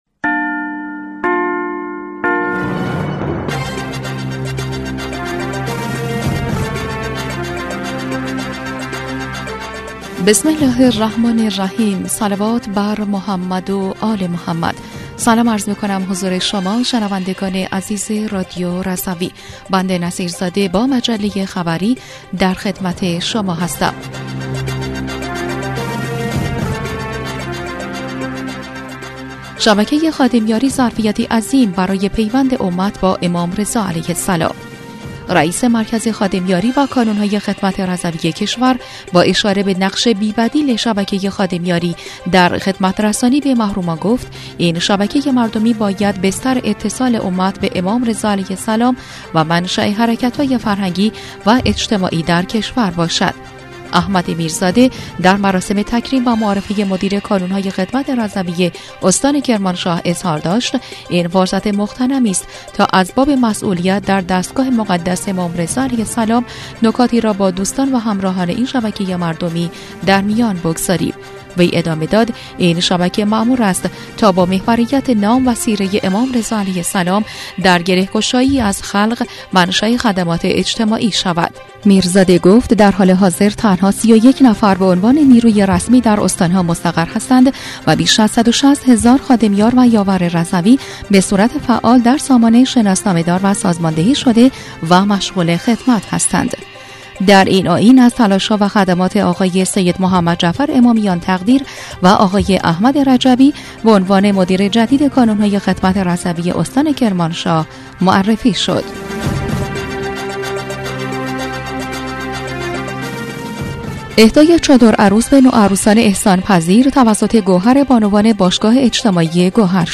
بسته خبری دوشنبه ۱۲ خرداد ۱۴۰۴ رادیو رضوی/